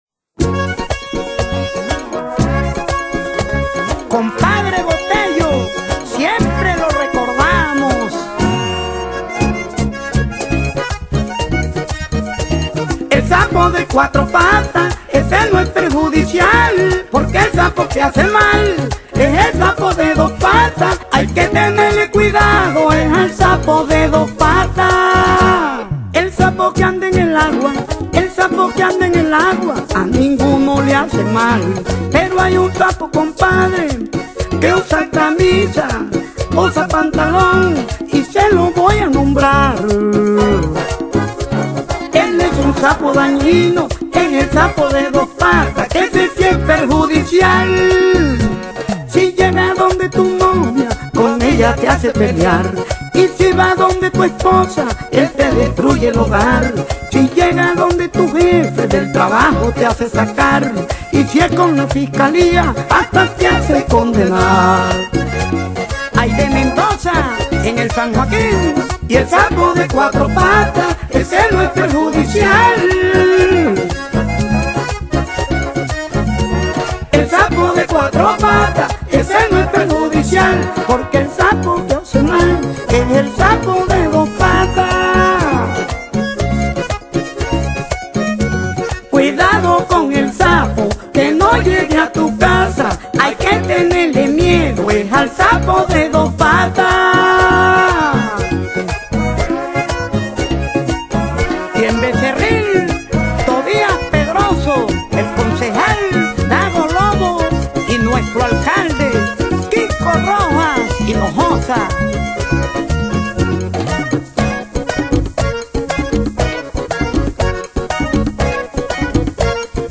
acordeón